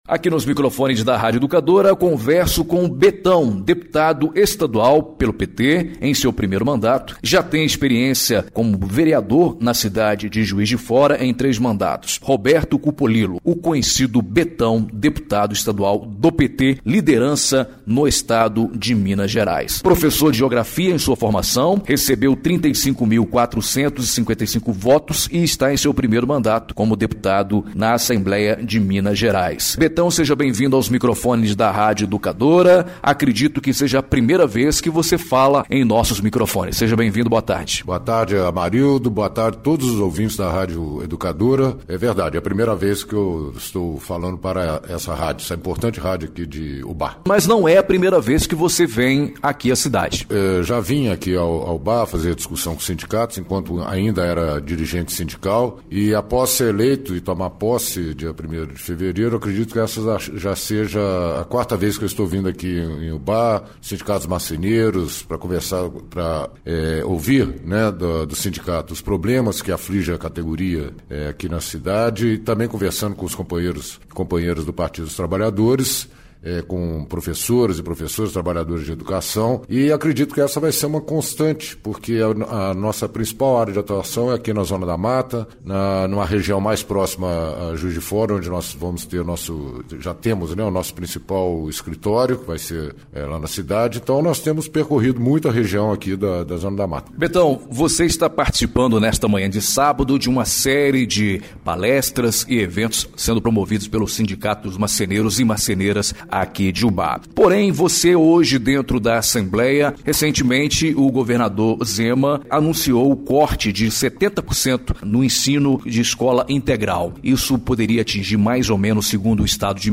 ENTREVISTA EXIBIDA NA RÁDIO EDUCADORA AM/FM UBÁ – MG